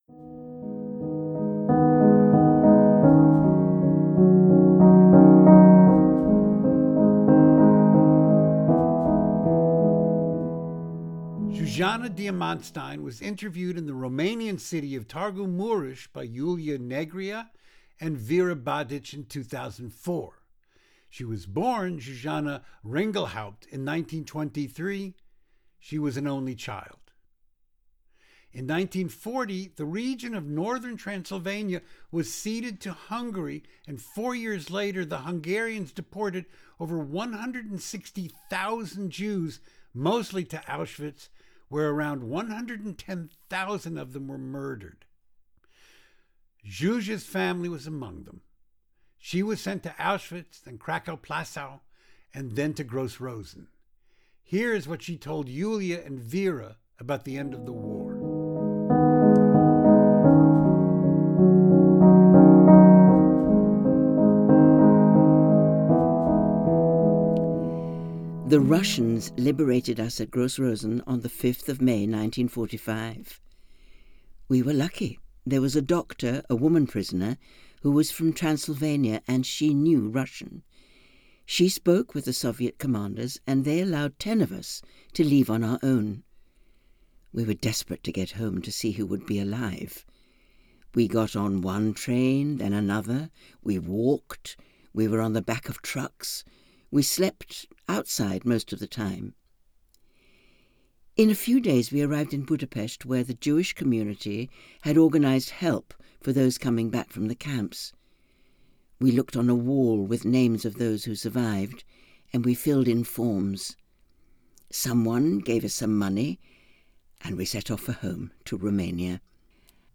We have translated and edited them and they are read for us by actors in London.